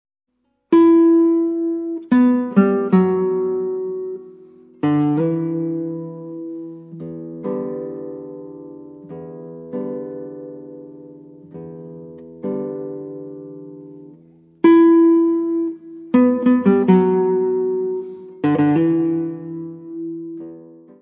guitarra.
Área:  Música Clássica